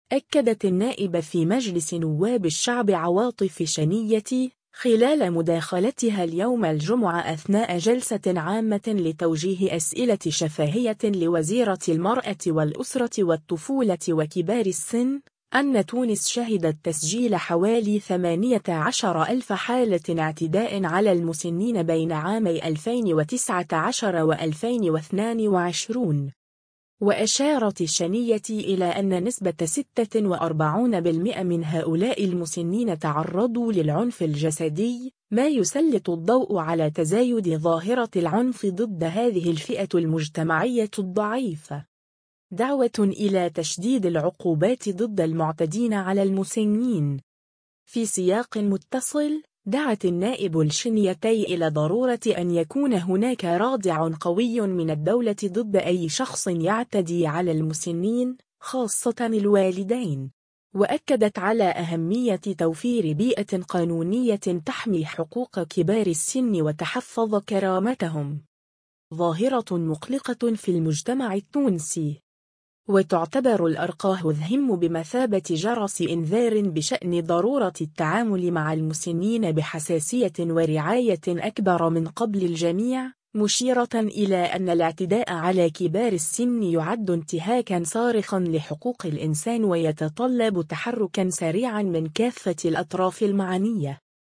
أكدت النائب في مجلس نواب الشعب عواطف الشنيتي، خلال مداخلتها اليوم الجمعة أثناء جلسة عامة لتوجيه أسئلة شفاهية لوزيرة المرأة والأسرة والطفولة وكبار السن، أن تونس شهدت تسجيل حوالي 18 ألف حالة اعتداء على المسنين بين عامي 2019 و2022.